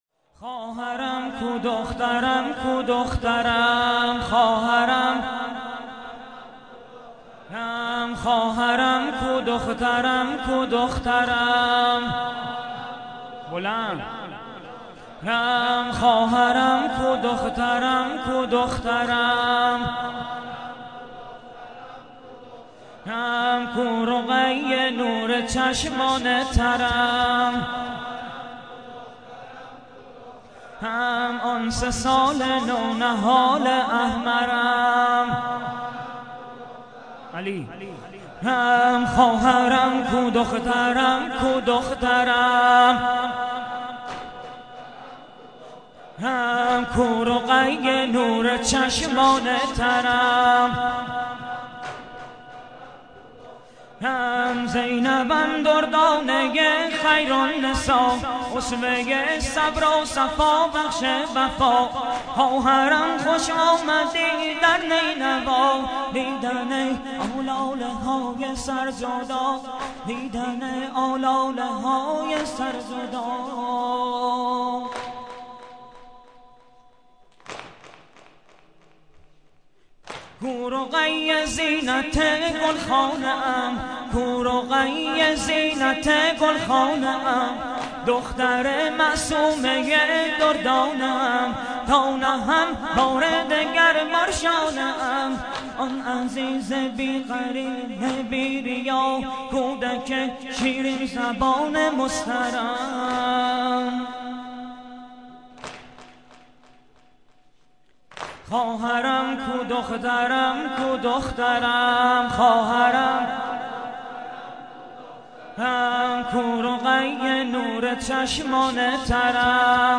متن و سبک نوحه اربعین -( خواهرم کو دخترم کو دخترم؟ )